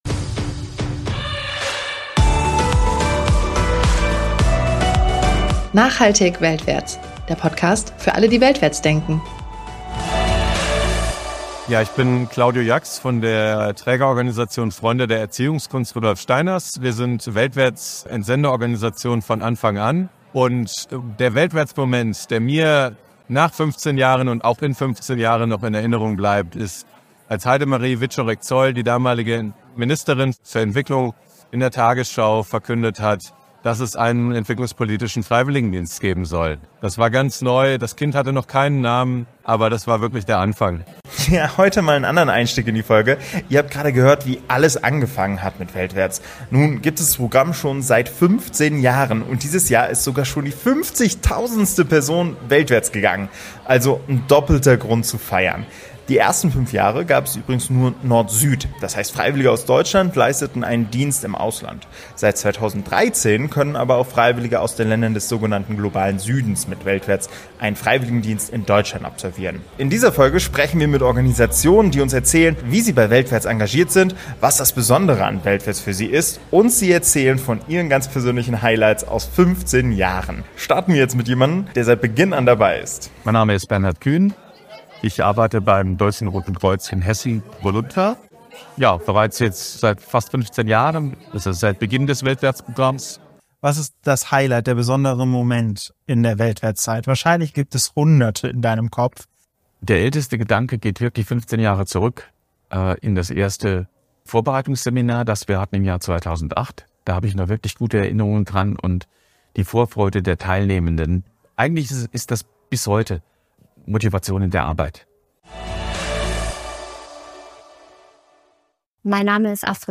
war auf der Offenen Trägertagung unterwegs und hat dort einige weltwärts-Organisationen vors Mikro bekommen.